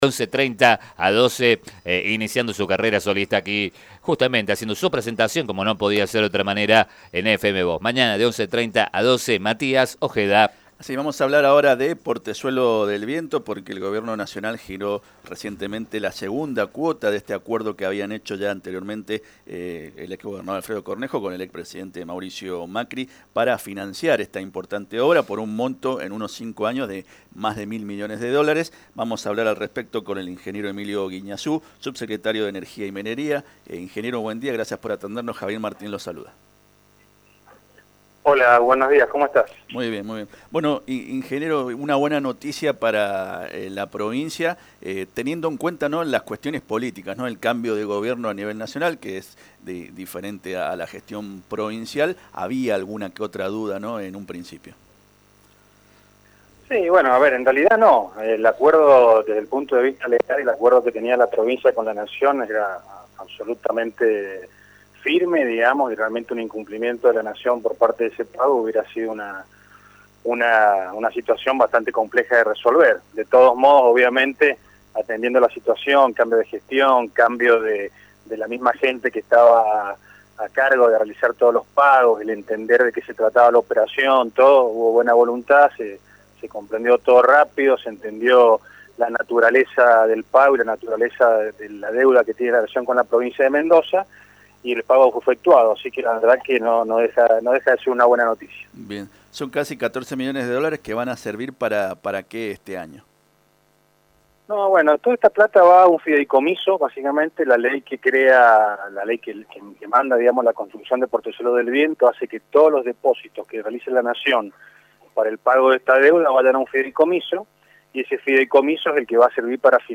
Por ley, todos los depósitos que hace Nación irán a un fideicomiso y se irá acumulando con el único fin de la construcción del proyecto; no puede ser destinado para otro gasto”, señaló el funcionario en FM Vos (94.5).